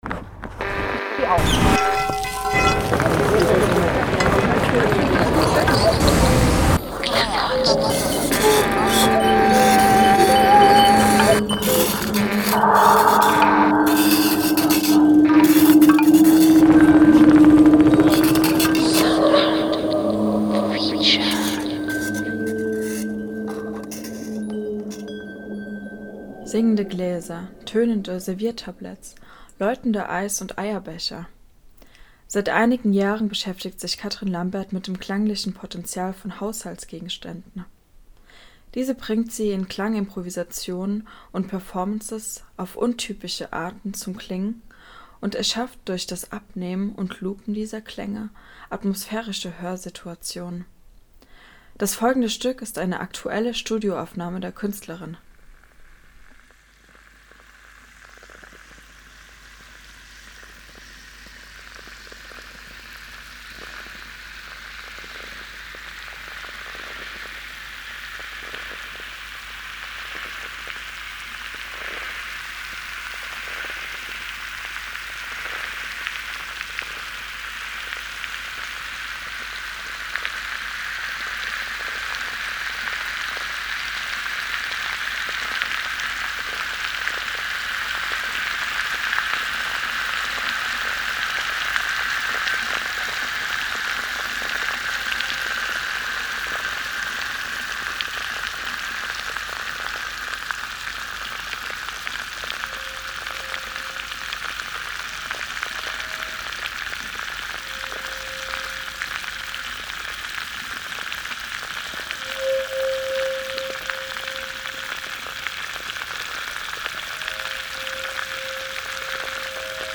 SoundArtFeature – klangliches aus dem Haushalt
Eine Studioaufnahme